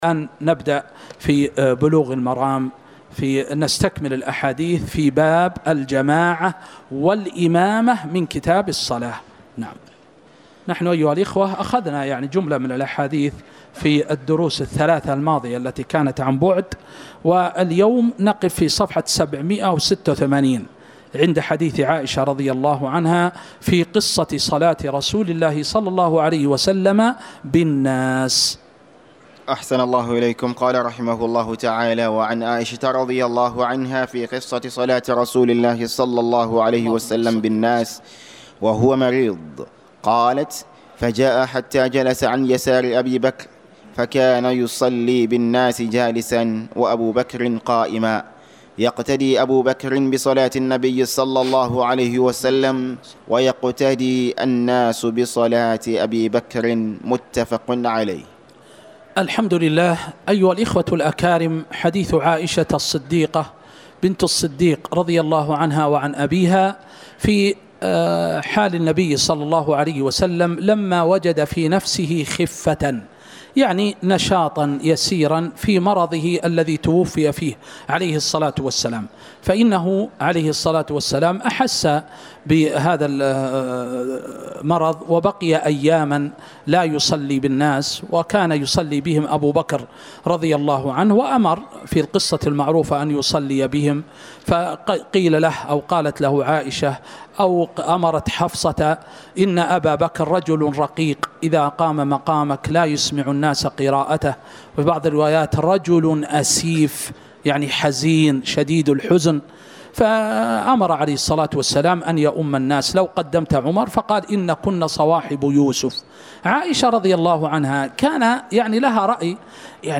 تاريخ النشر ١٠ جمادى الآخرة ١٤٤٥ هـ المكان: المسجد النبوي الشيخ